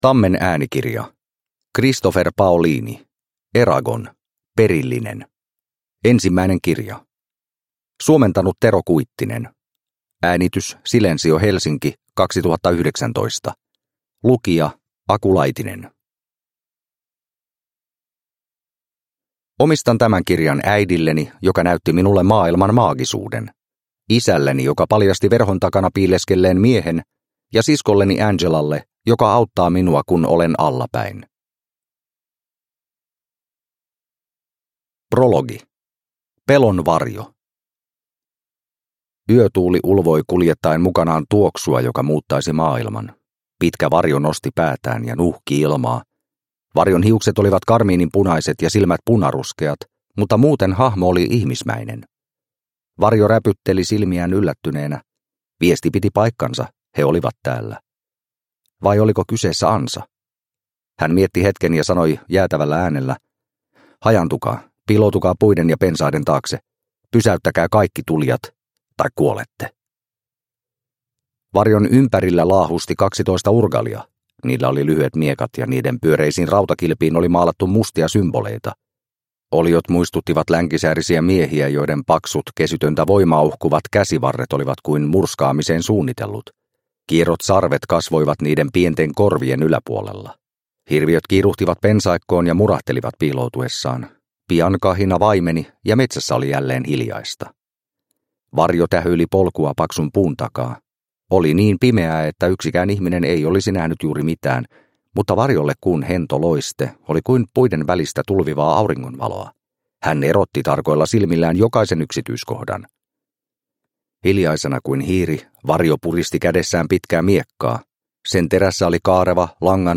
Eragon – Ljudbok – Laddas ner